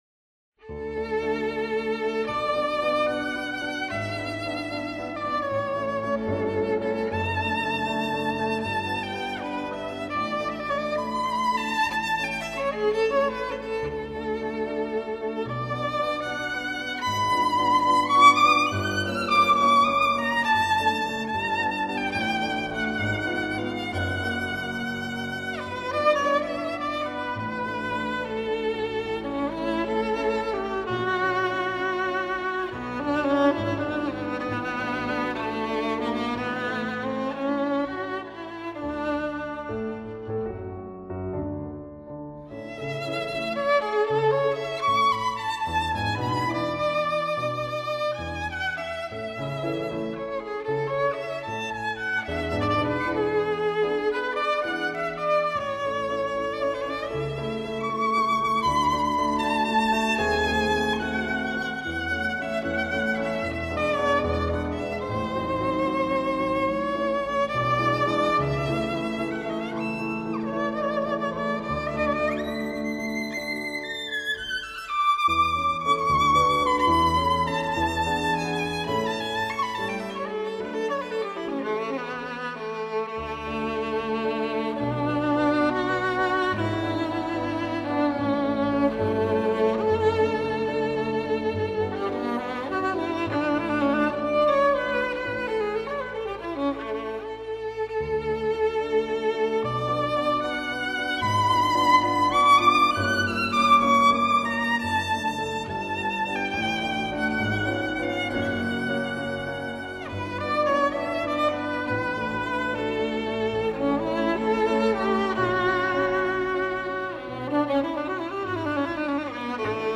曲调优美，演奏细腻，丝丝入扣